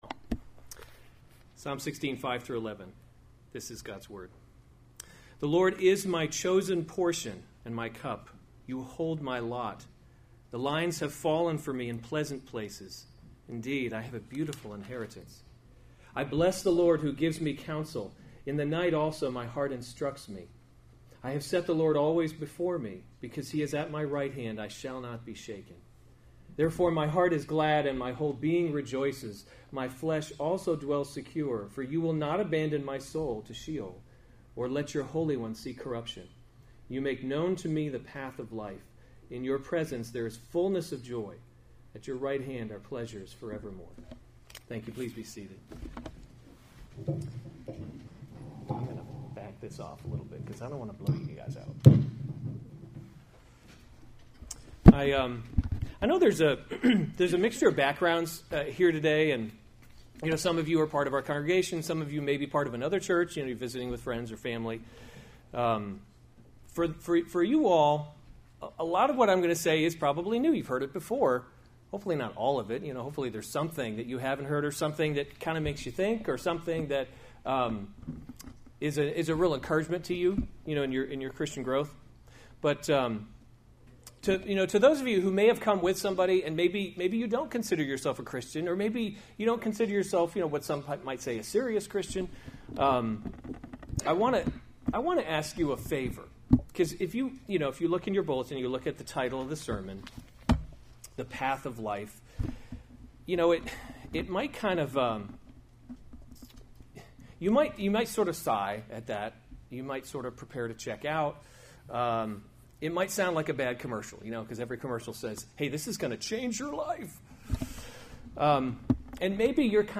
April 15, 2017 Special Services series Easter Service Save/Download this sermon Psalm 16 Other sermons from Psalm You Will Not Abandon My Soul A Miktam [1] of David. 16:1 Preserve me, O […]